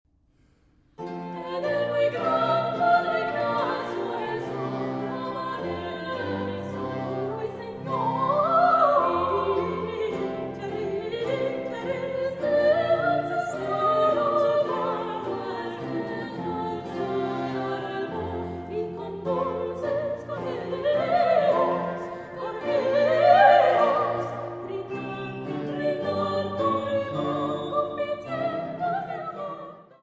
The choral textures are thrilling’ (Oxford Today)